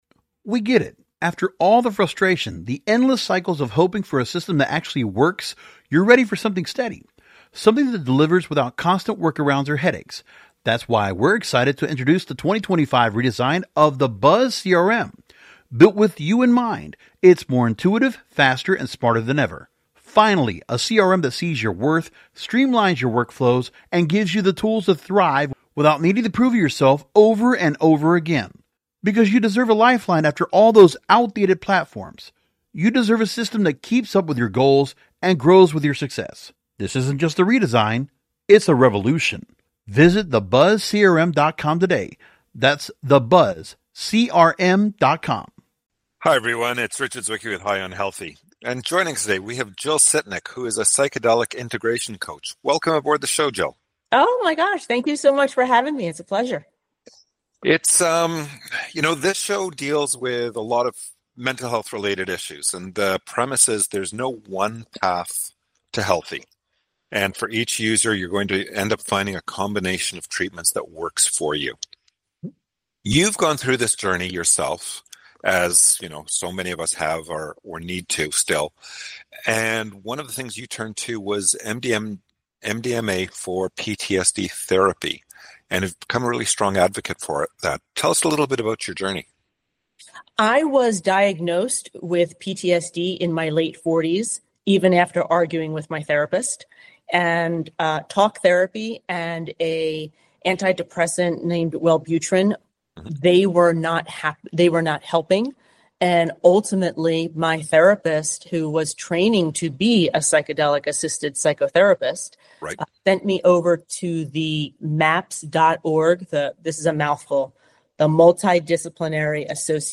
Mental Health Interviews